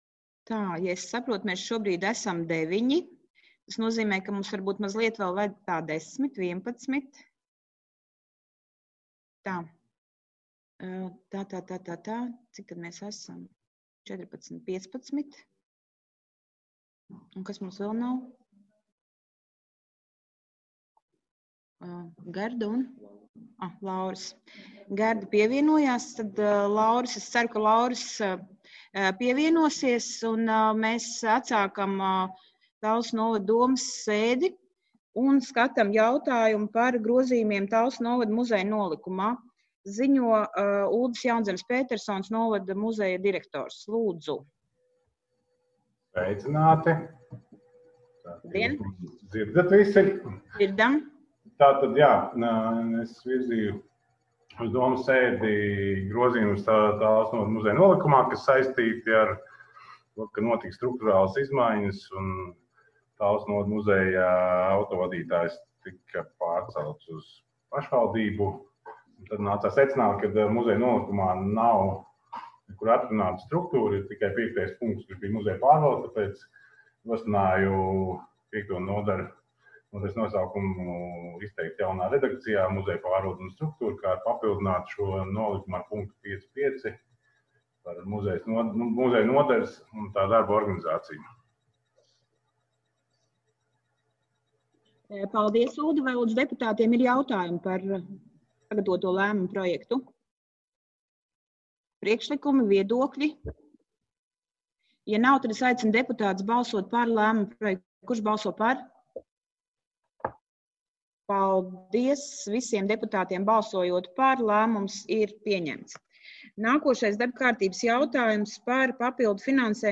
Balss ātrums Publicēts: 26.11.2020. Protokola tēma Domes sēde Protokola gads 2020 Lejupielādēt: 27.